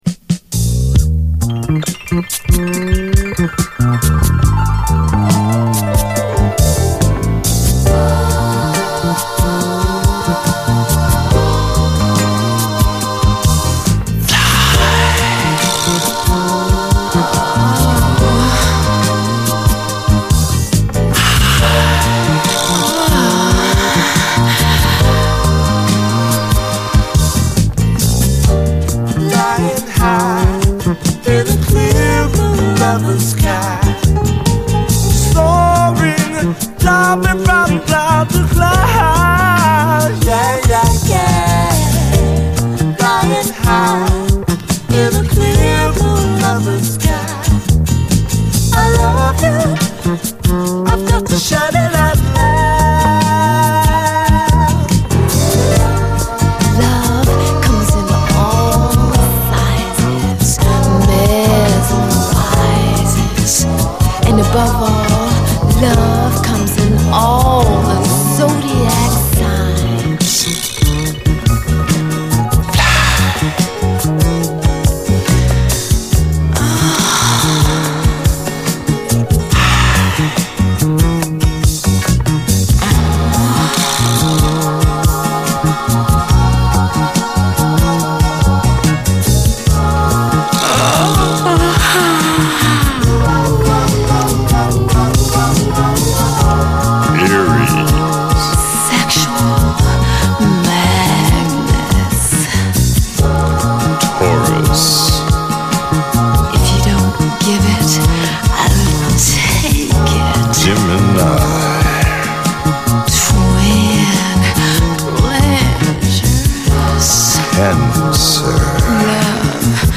特にこの3RDこそ、美メロ＆美ハーモニーの60’Sクロスオーヴァー・ソウル揃いで全編最高な名盤！
胸を締め付ける最高スウィート・クロスオーヴァー・ソウル